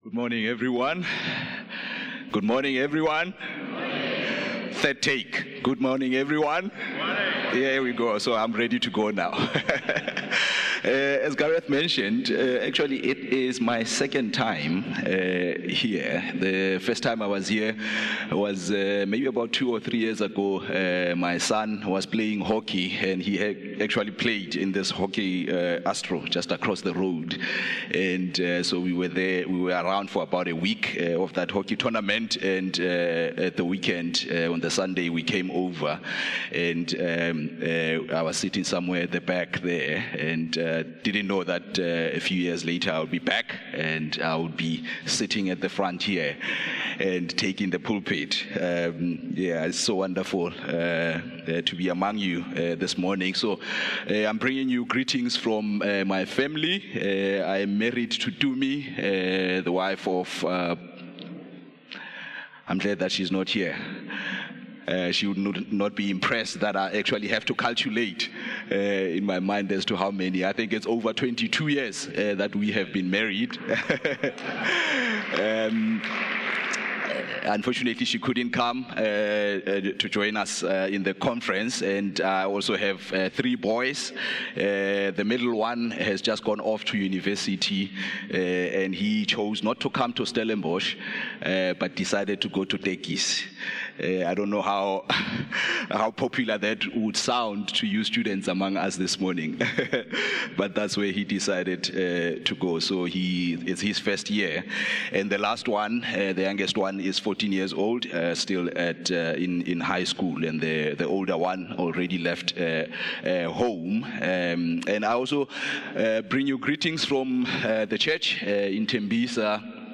From Series: "Standalone Sermons"
One-Hope-Sermon-1-June-2025.mp3